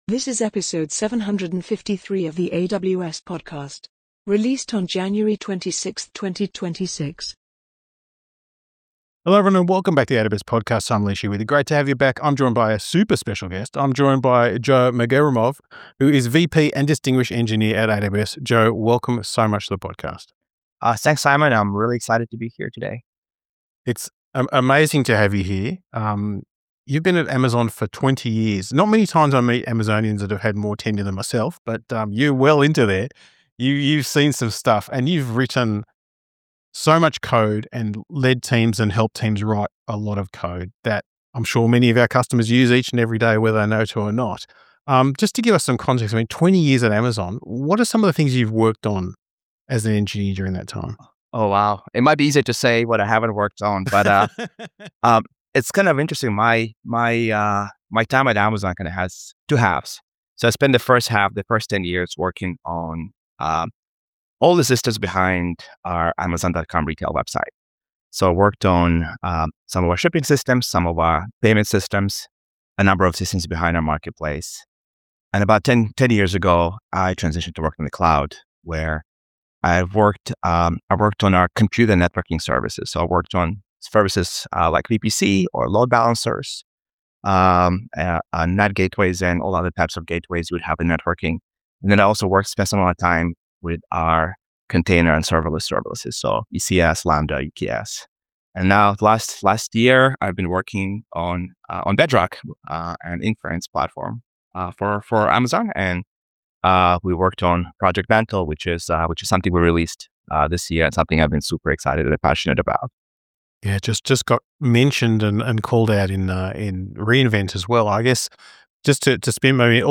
We dive deep into the critical infrastructure changes needed to sustain high-velocity development, including the mathematics of bug probability at scale, innovative testing approaches inspired by aviation industry practices, and the evolution of CI/CD pipelines that can handle dozens of commits per hour rather than per day. The conversation